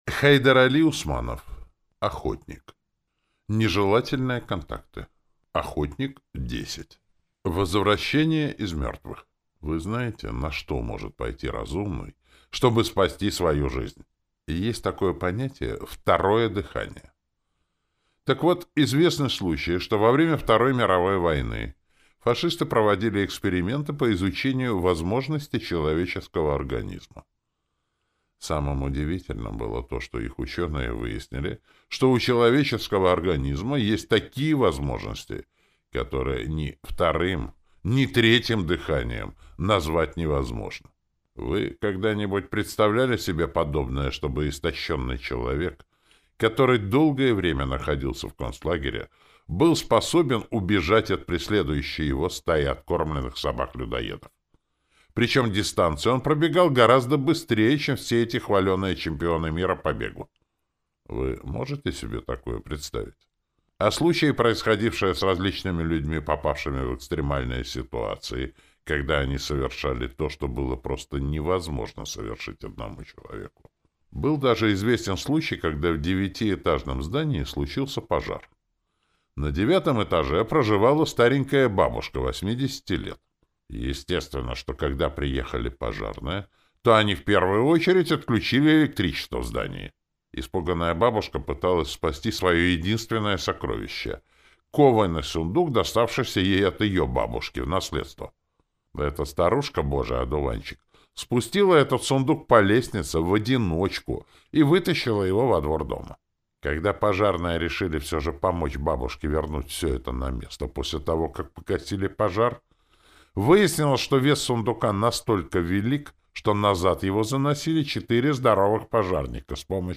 Аудиокнига Охотник. Нежелательные контакты | Библиотека аудиокниг